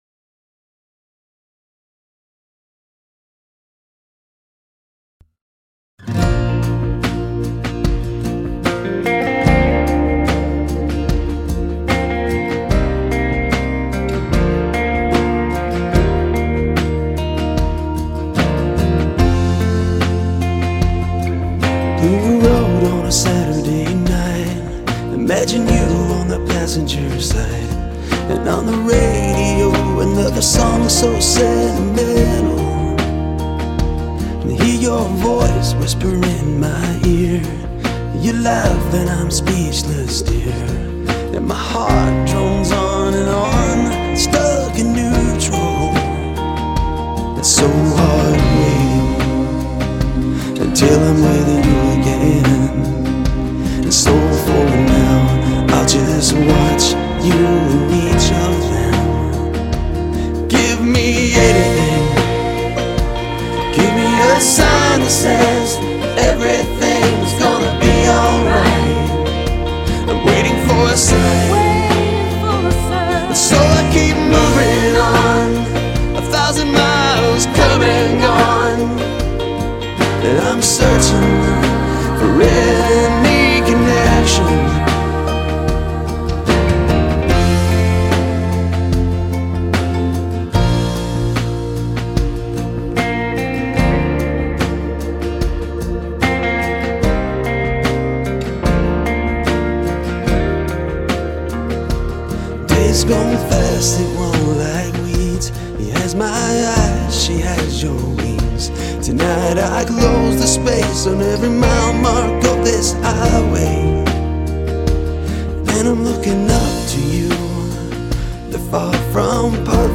Genre: Singer Songwriter